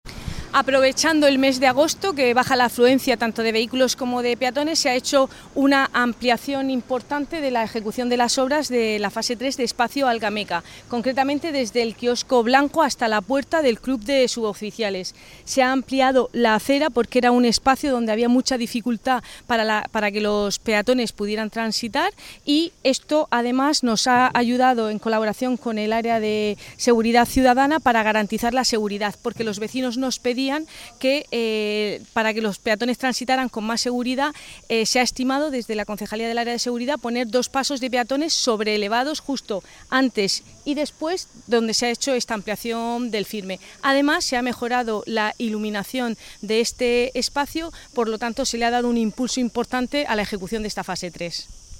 Enlace a Declaraciones de Cristina Mora, concejal del área de Política Social, Familia e Igualdad